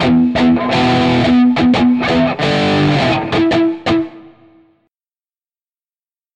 Захватывающий звук электрогитары на примере